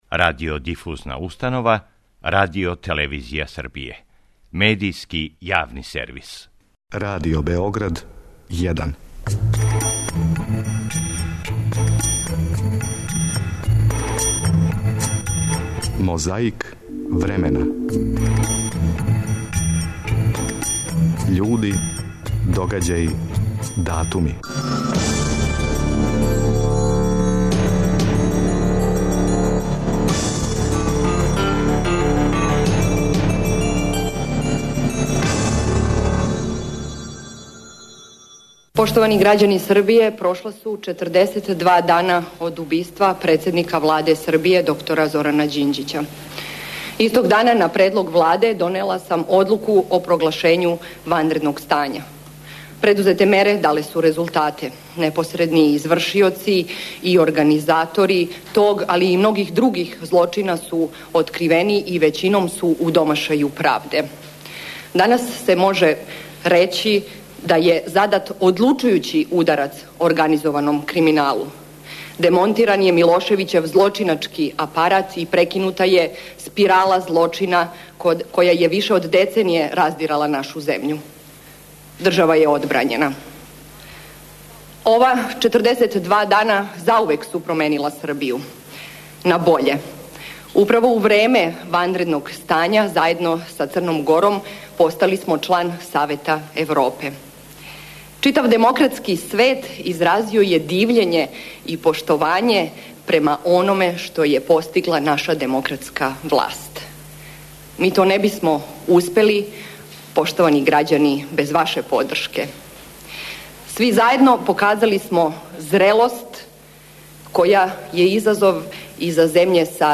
22. априла 1966, у суседној Румунији, у палати "Република" у Букурешту одржан је митинг на коме су говорили Николае Чаушеску и Јосип Броз Тито. Напомињемо - протокол био без грешке, тако да су и домаћин и гост могли да буду сасвим задовољни развојем речи и аплауза!
Подсећа на прошлост (културну, историјску, политичку, спортску и сваку другу) уз помоћ материјала из Тонског архива, Документације и библиотеке Радио Београда.